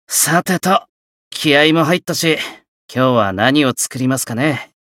觉醒语音 さてと。